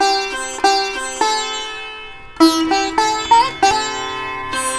100-SITAR1-L.wav